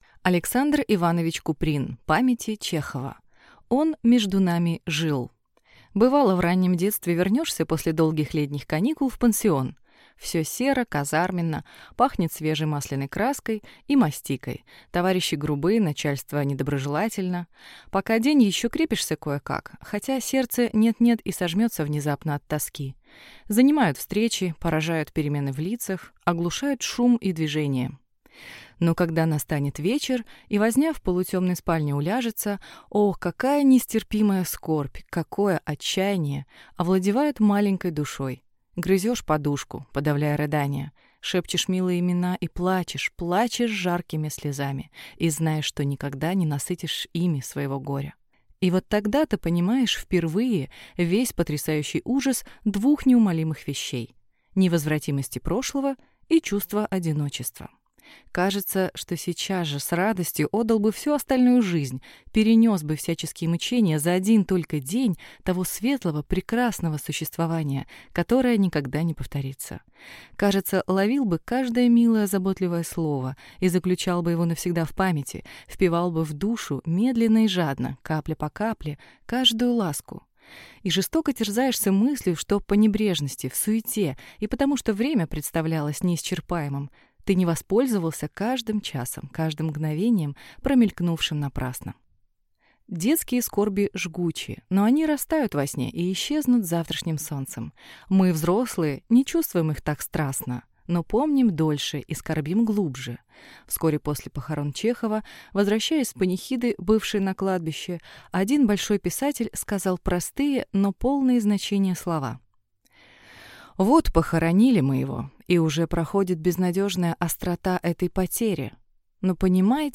Аудиокнига Памяти Чехова | Библиотека аудиокниг
Прослушать и бесплатно скачать фрагмент аудиокниги